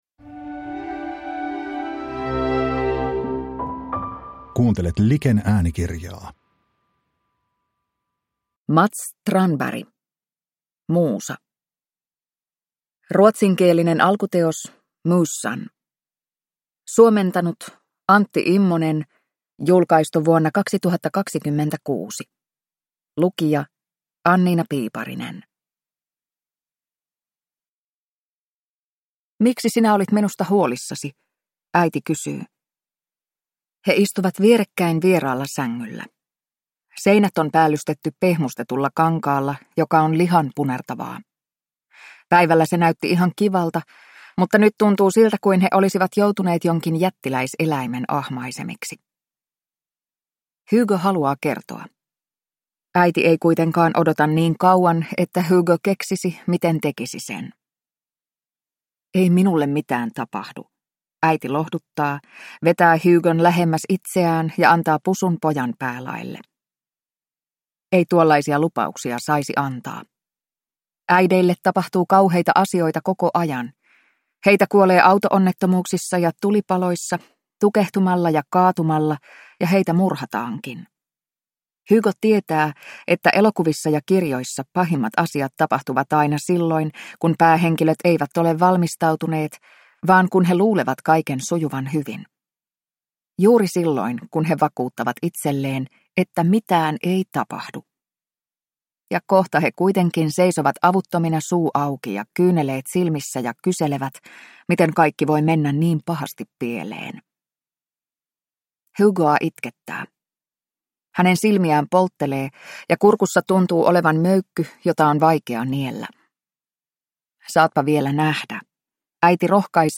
Muusa (ljudbok) av Mats Strandberg